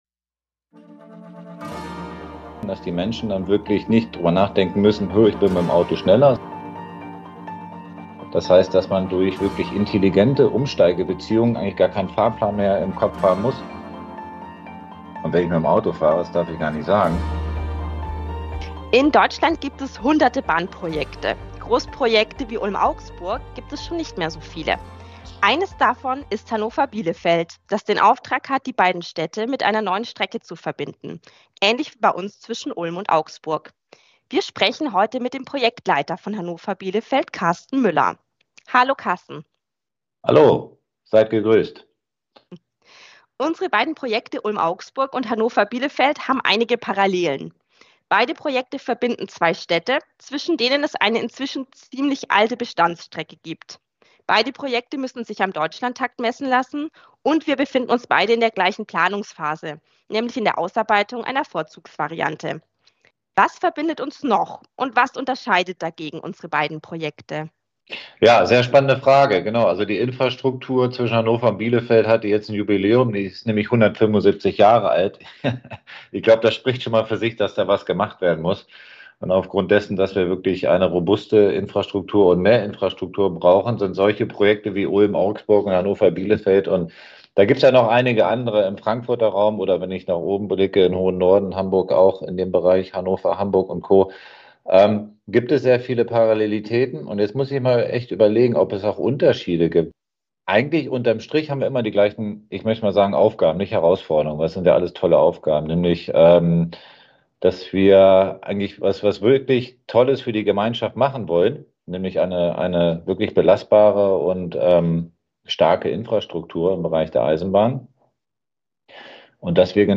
Zu den großen gehören Ulm-Augsburg und Hannover-Bielefeld. Ein Gespräch